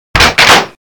Download Half Life Shotgun Ready sound effect for free.
Half Life Shotgun Ready